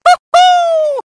One of Diddy Kong's voice clips in Mario Kart: Double Dash!!